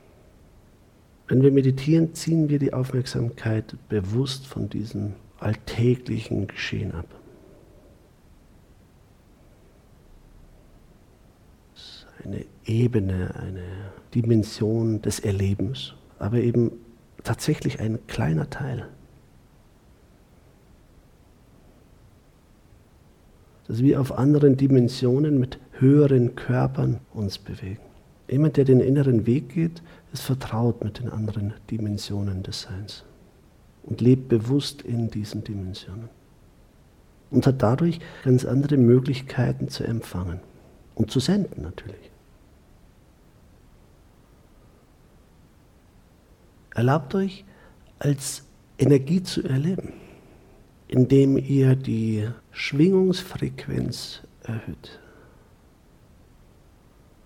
Meditation Live-Aufnahmen
Die längeren Pausen in dieser Meditation geben viel Zeit, um sich tief auf diesen Prozess einzulassen.